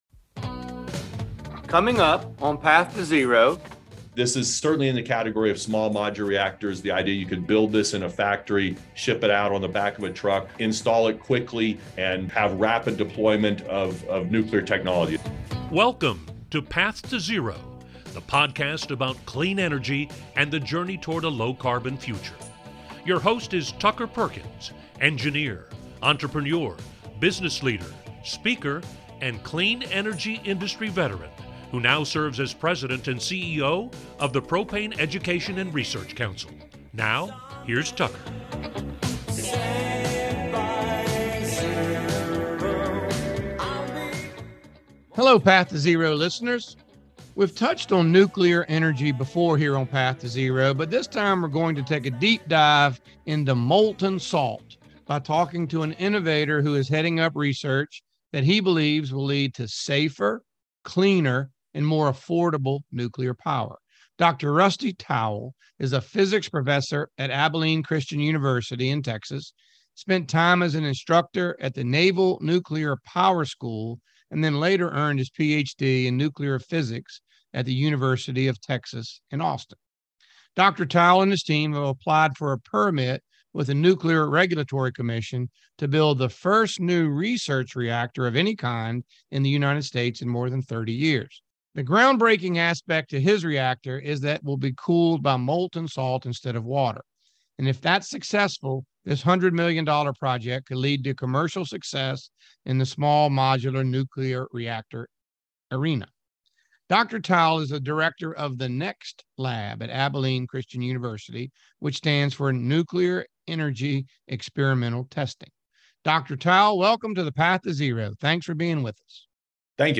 4.21 - Could Molten Salt Reactors Boost Nuclear Energy? A conversation